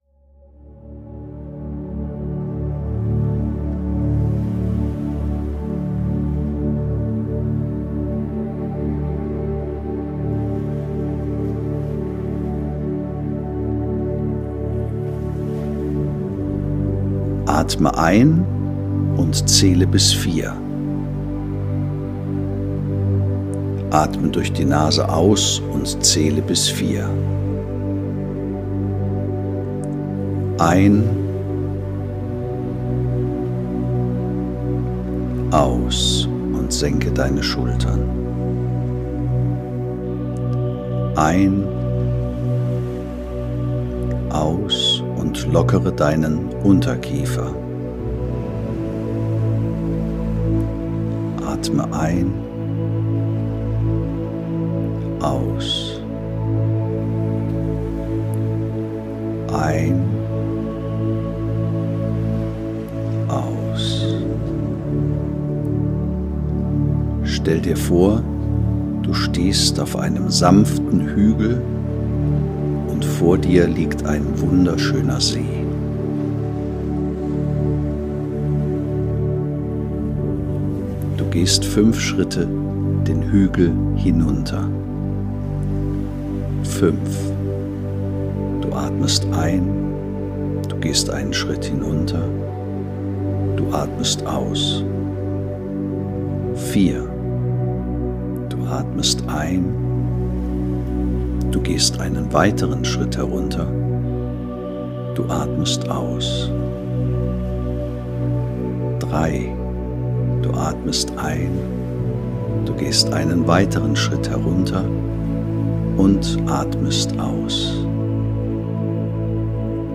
Meditationen
meditation-bodo-schaefer.mp3